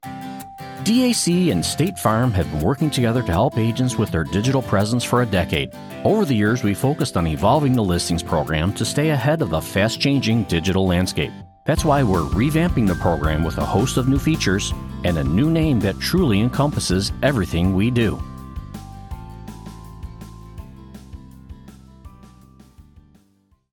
American Male Voice Over Artist
I work out of a broadcast quality home studio with professional recording equipment and a quick turnaround time!